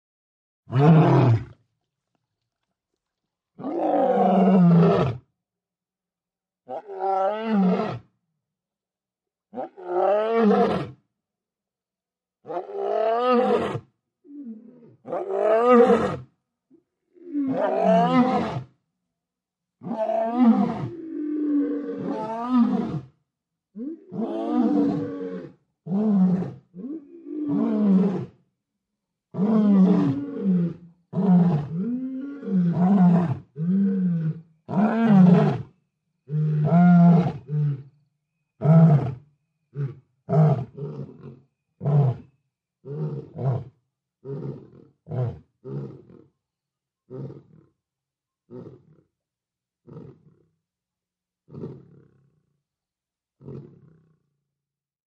ANIMALS WILD: Male lion, full roaring sequence, second lion joins in Zimbabwe.